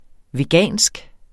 Udtale [ veˈgæˀnsg ]